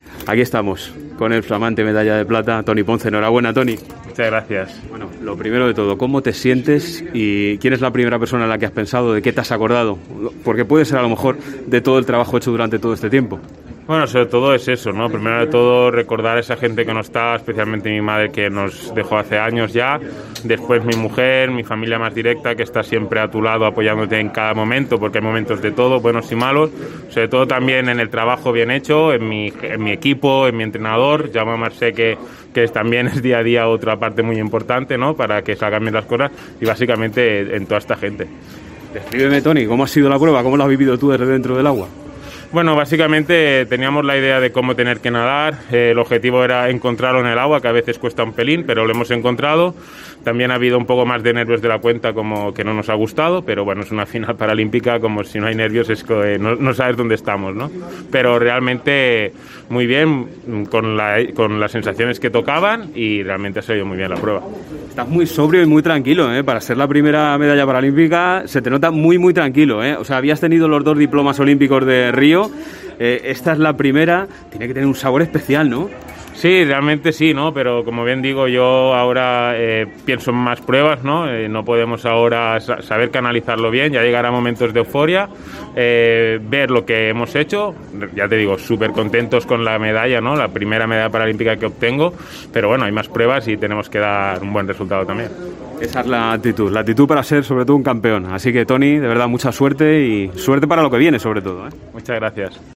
El nadador ha comentado en COPE su sensación durante la prueba en la que ha conseguido la medalla de plata, su primer metal olímpico.